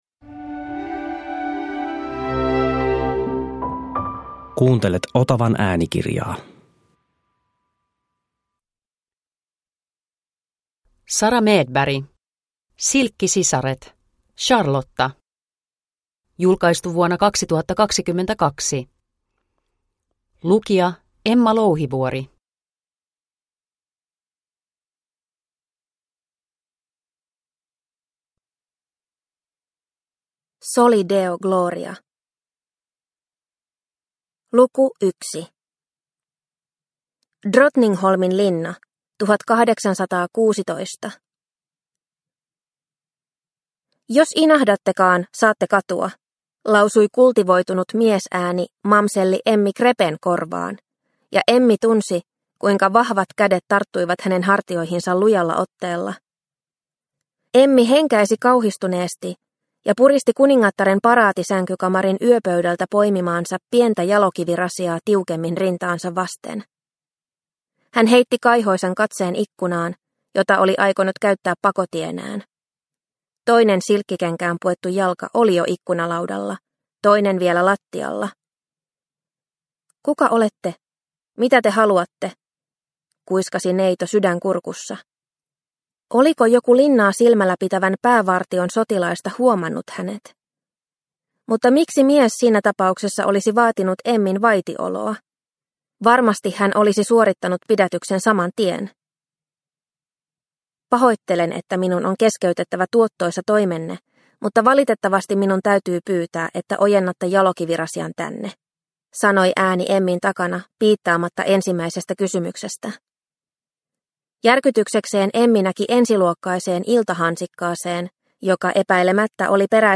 Silkkisisaret - Charlotta – Ljudbok – Laddas ner